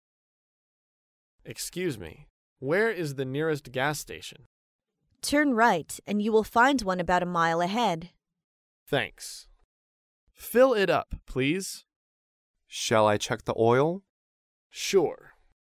在线英语听力室高频英语口语对话 第398期:驾车加油的听力文件下载,《高频英语口语对话》栏目包含了日常生活中经常使用的英语情景对话，是学习英语口语，能够帮助英语爱好者在听英语对话的过程中，积累英语口语习语知识，提高英语听说水平，并通过栏目中的中英文字幕和音频MP3文件，提高英语语感。